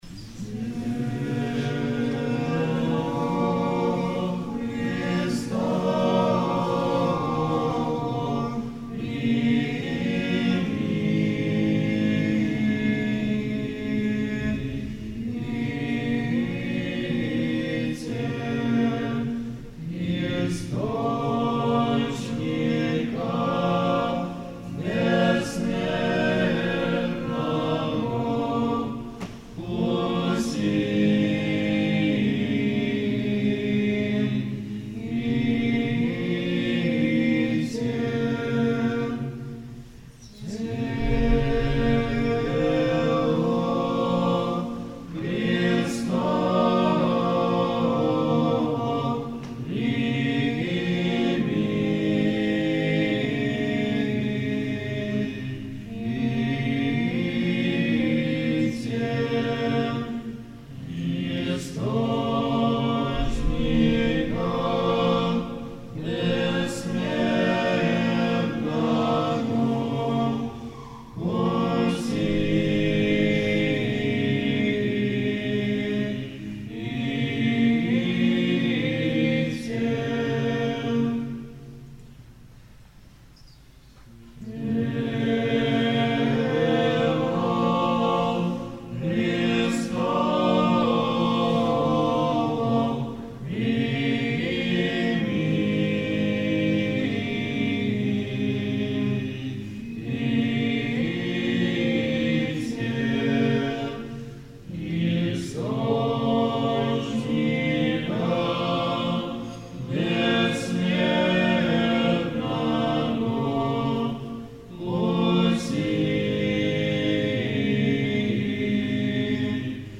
Аудио записи хора монастыря